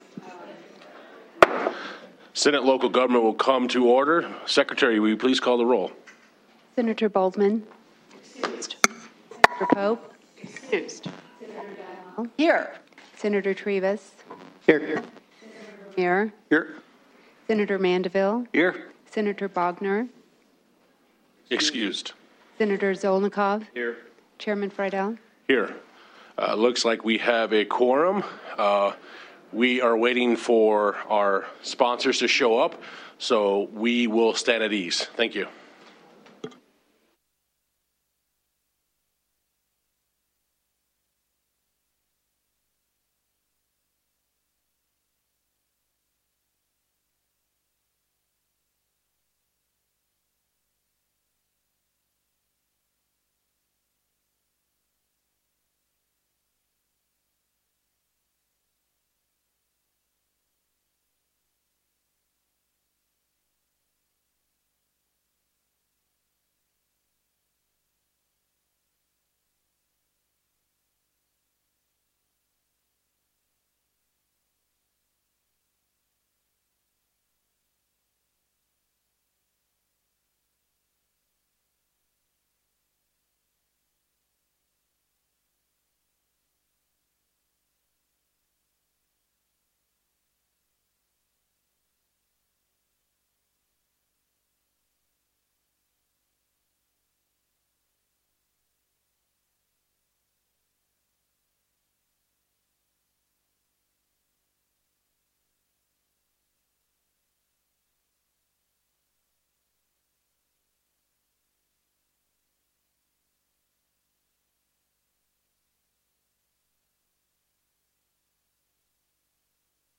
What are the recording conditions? Senate Local Government